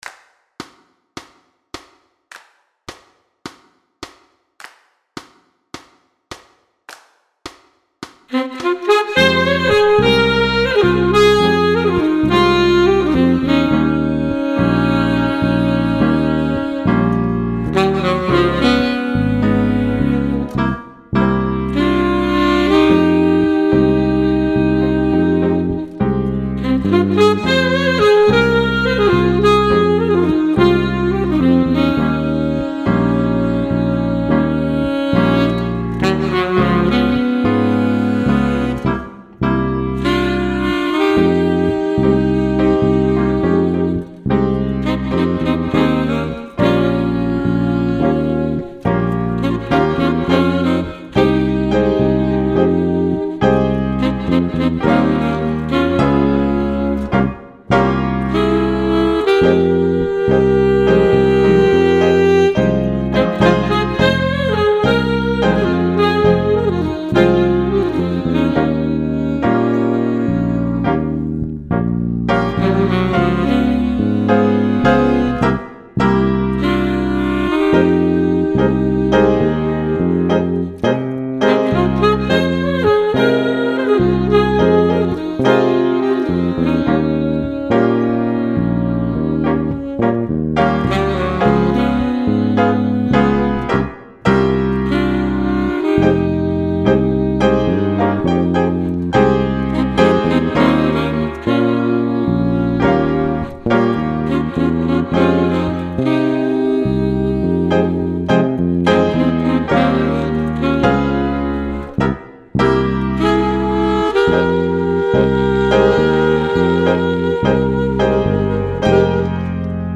ohne Schlagzeug Weiterlesen »